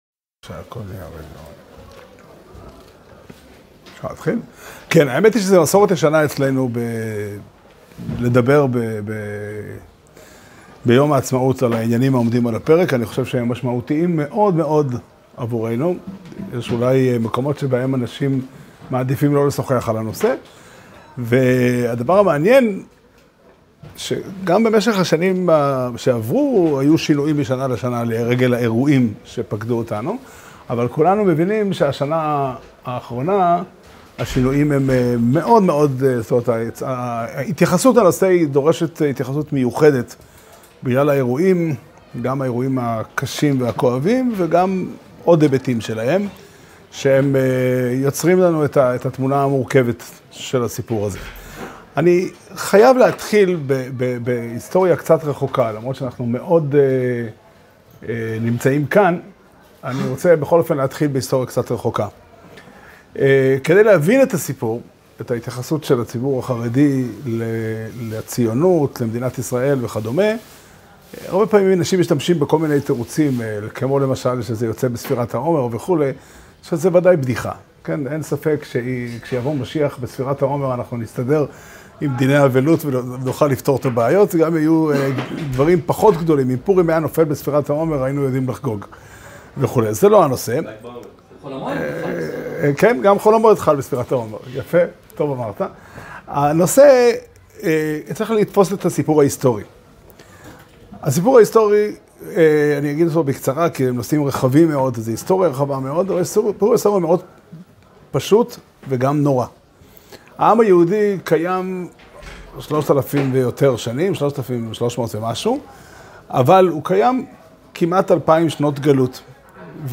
שיעור שנמסר בבית המדרש פתחי עולם בתאריך ו' באייר תשפ"ד